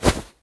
fire2.ogg